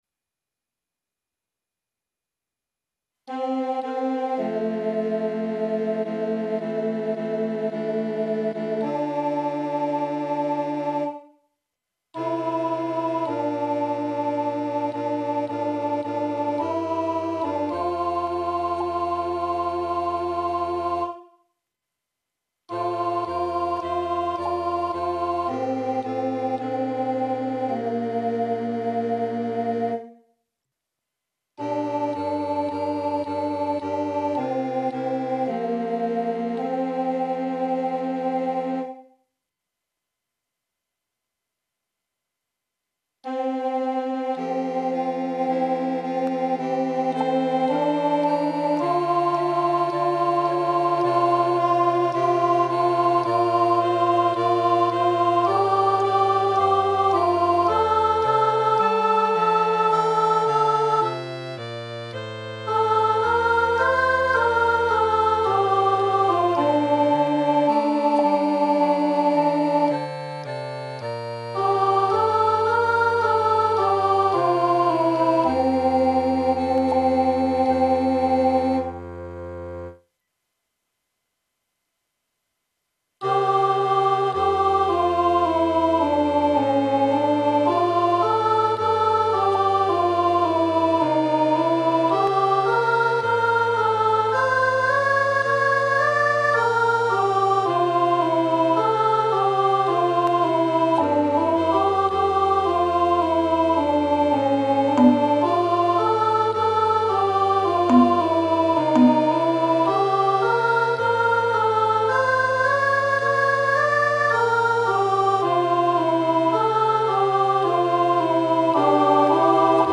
vierstemmig gemengd zangkoor